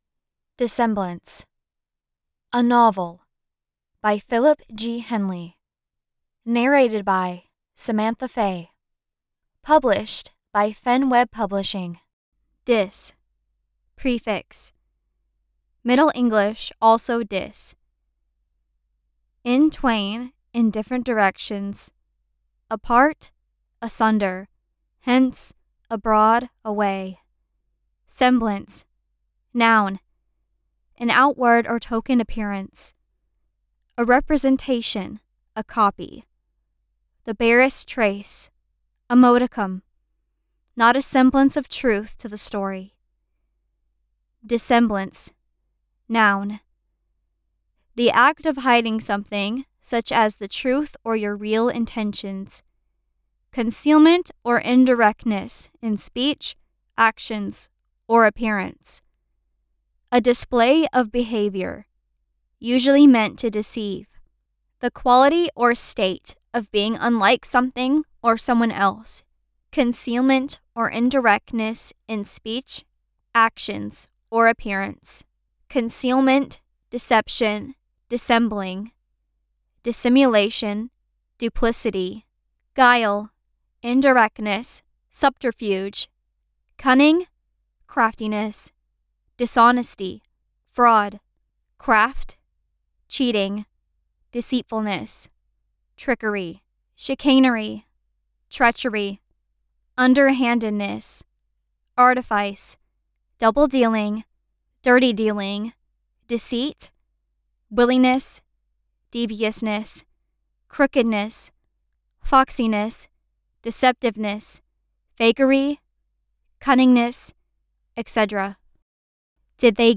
An audio book version and translations are now available on Amazon, Apple, and Audible sites.